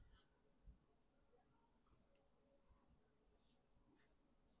blank.wav